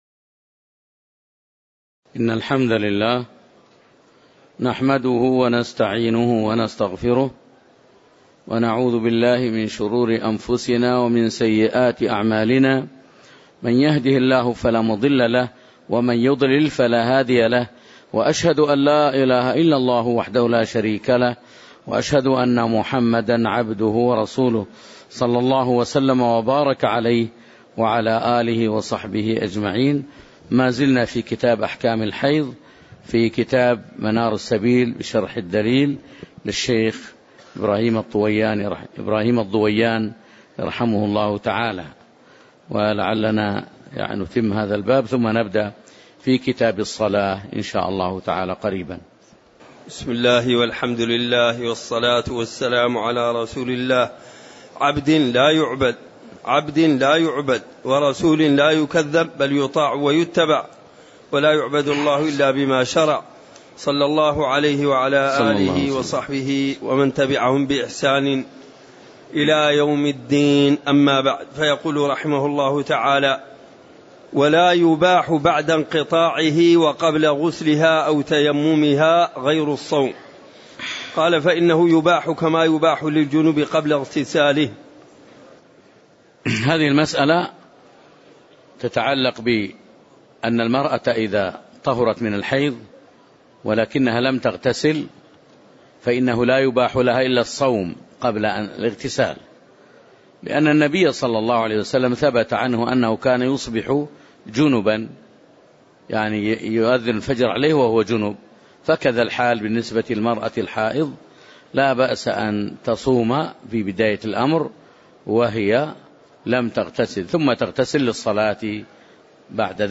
تاريخ النشر ٢٣ شعبان ١٤٣٨ هـ المكان: المسجد النبوي الشيخ